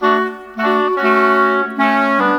Rock-Pop 10 Winds 02.wav